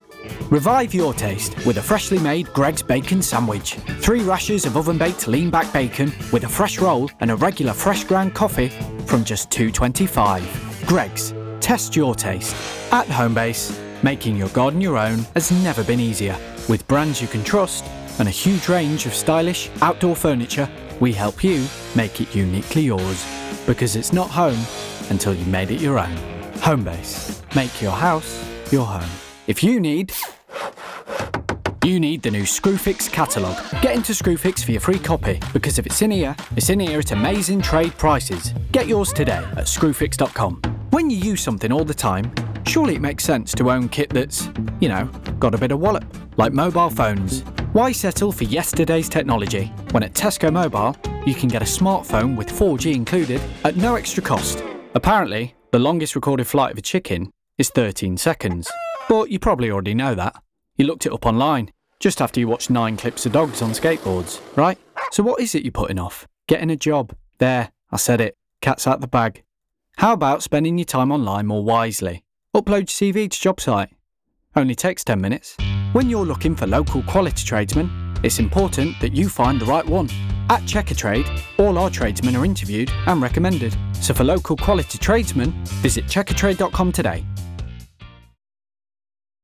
• Baritone - High
Voice Reel